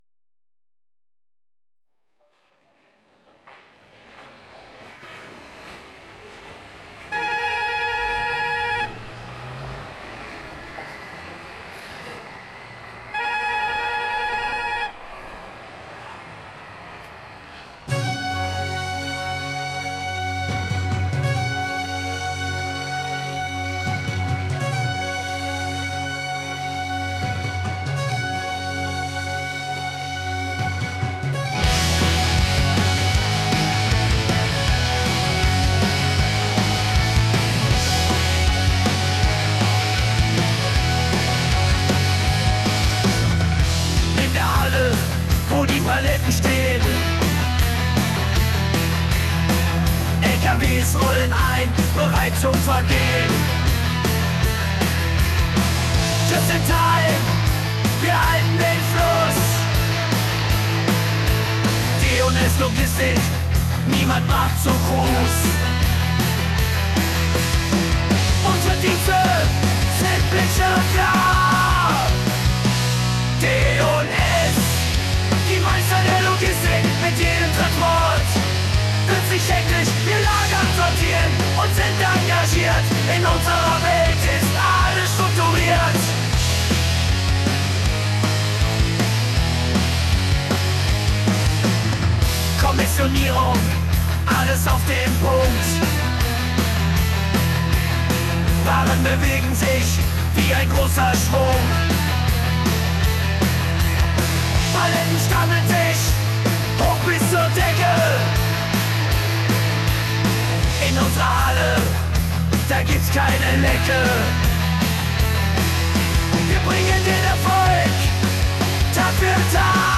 Auf vielfachen Wunsch haben wir es endlich möglich gemacht: Unser beliebtes Warteschleifenlied könnt ihr jetzt hier anhören!